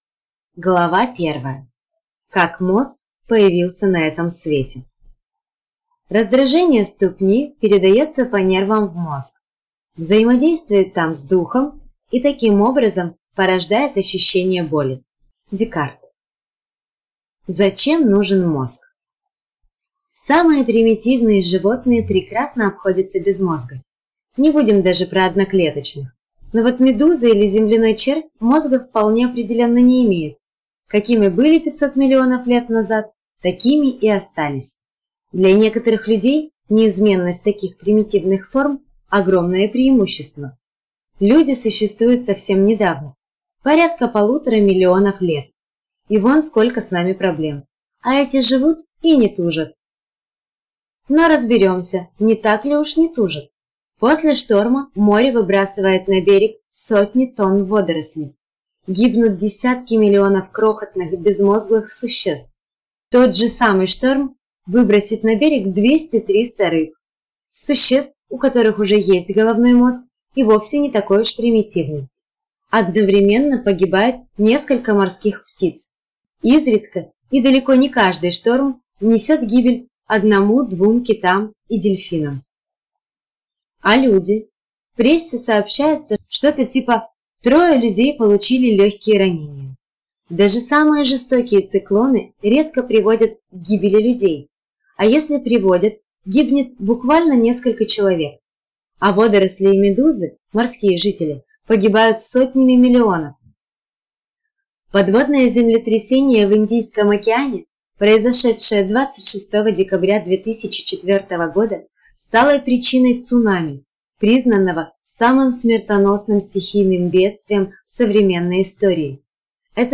Аудиокнига Феномен мозга. Тайны 100 миллиардов нейронов | Библиотека аудиокниг